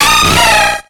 Cri d'Azumarill dans Pokémon X et Y.